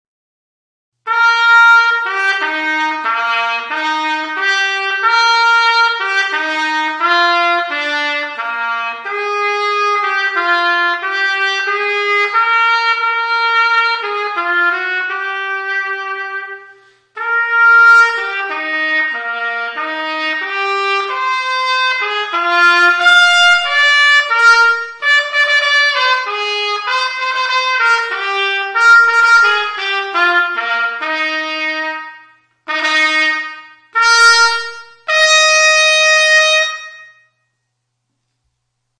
Hejnał już w sobotę